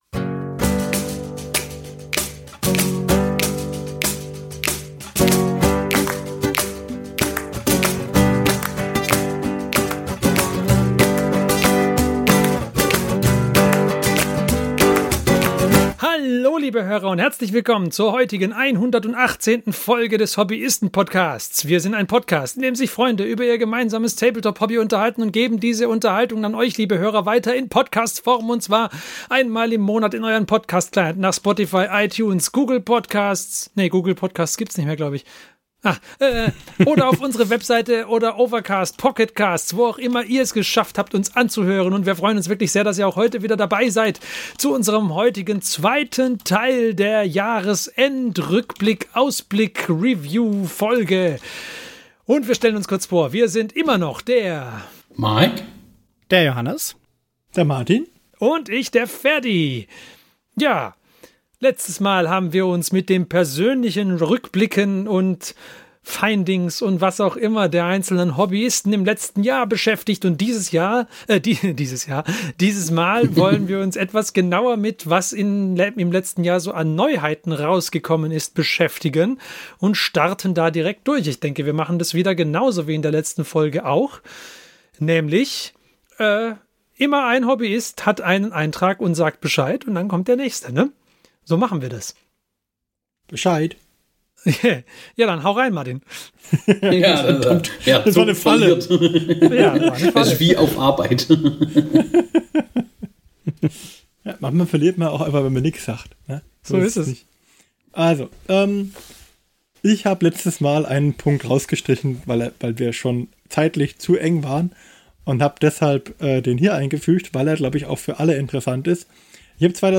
Du hörst die Kerkerkumpels, das Pen & Paper Hörspiel. Die Geschichte, die du hörst, ist live improvisiert.